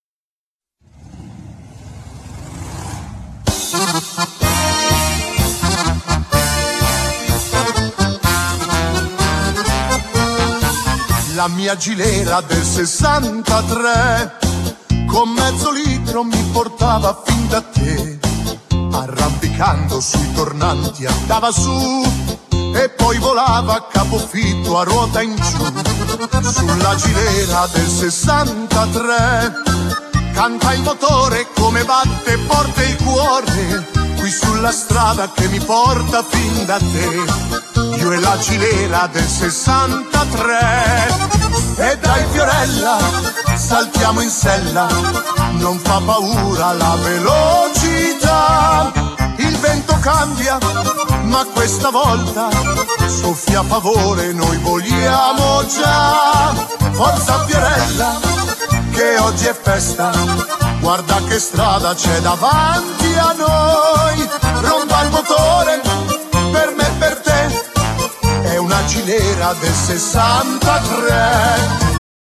Genere : Liscio folk